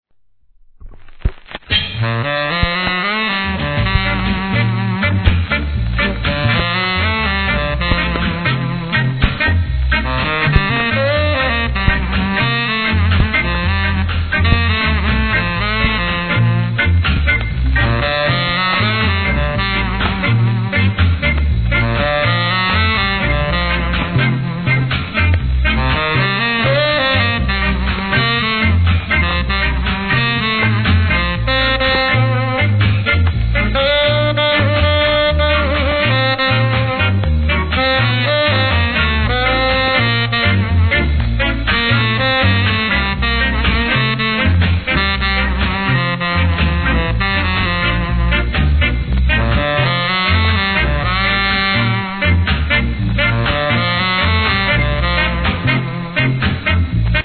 C (PLAY:B) / 序盤に傷で周期的なプツ有りますが落ち着きます。音圧あります
1. REGGAE